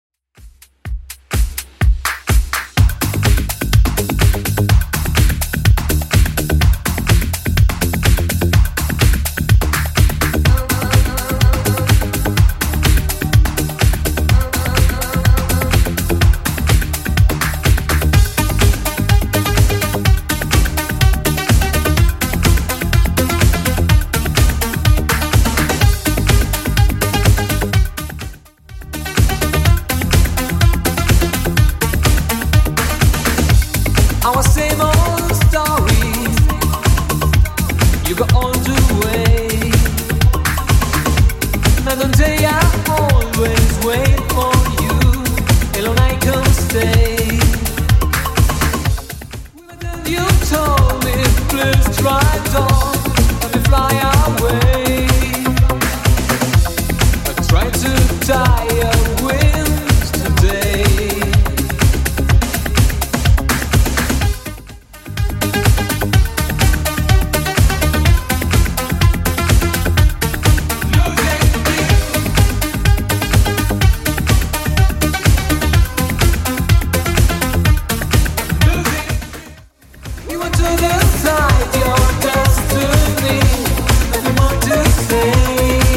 Genre: 80's
BPM: 102